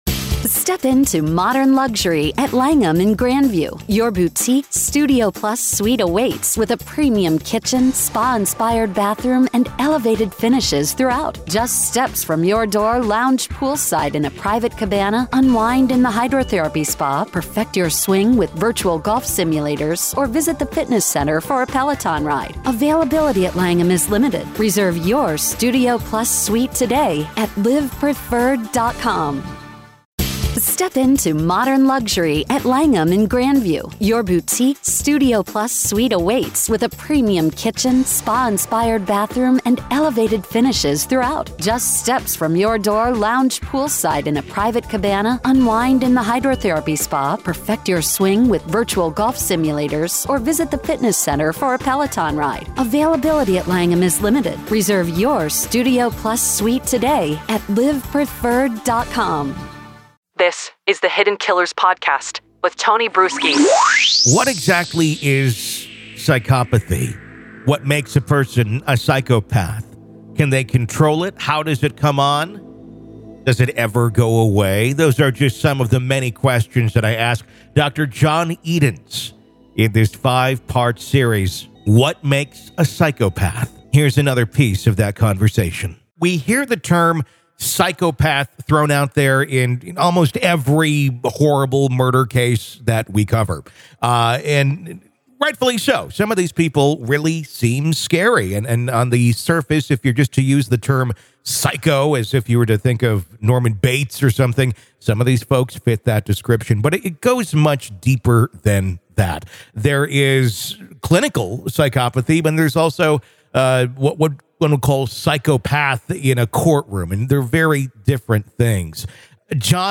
What Makes A Psychopath Conversation